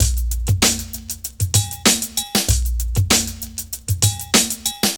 SOUL QRG.wav